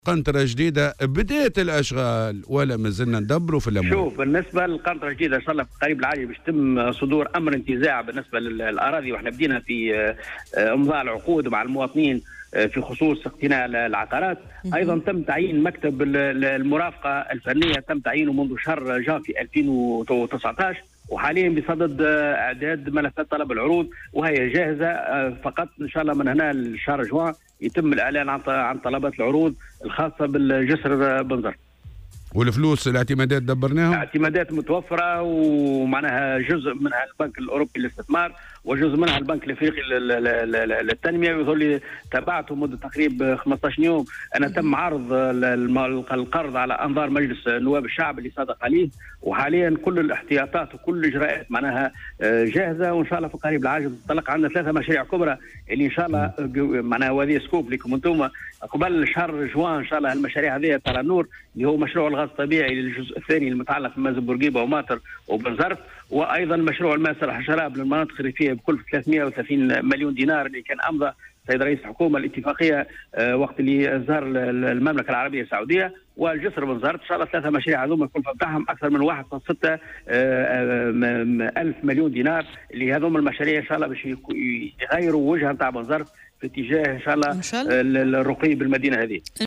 وأضاف في مداخلة له اليوم في برنامج "صباح الورد" على "الجوهرة أف أم" أن هذه المشاريع تهم إنجاز القسط الثاني من مشروع ربط عدد من المناطق بالغاز الطبيعي و تزويد بعض المناطق الريفية بالماء الصالح للشراب (بكلفة 330 مليون دينار) إضافة إلى مشروع جسر بنزرت الجديد.